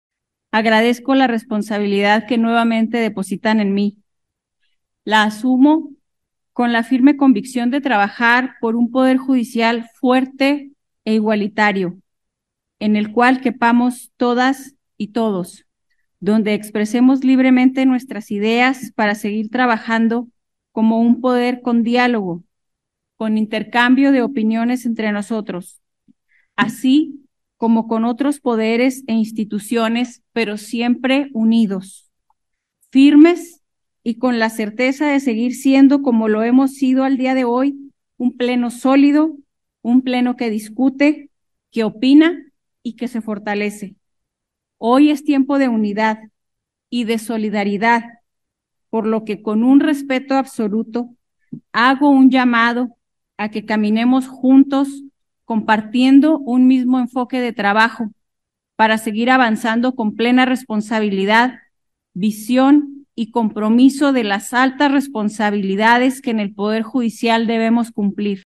AUDIO: MYRIAM HERNÁNDEZ, PRESIDENTA MAGISTRADA DEL TRIBUNAL SUPERIOR DE JUSTICIA (TSJ), DEL PODER JUDICIAL